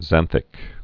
(zănthĭk)